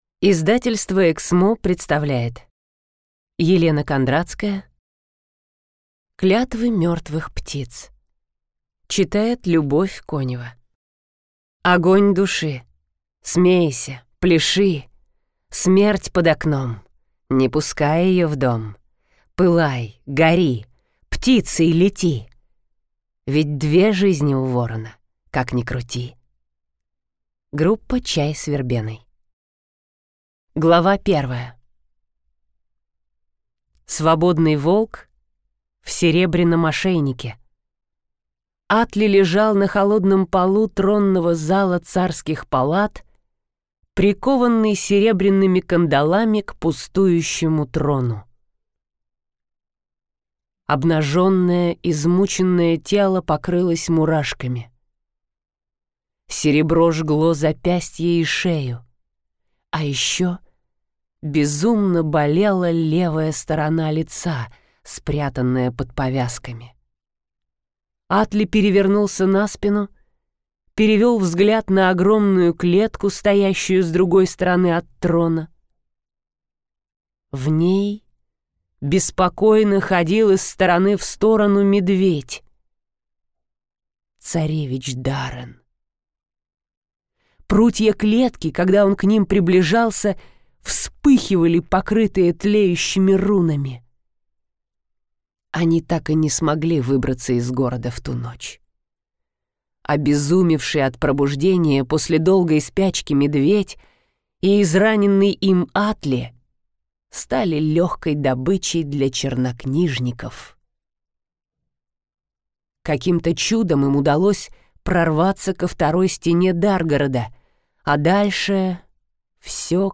Клятвы мертвых птиц - слушать аудиокнигу бесплатно онлайн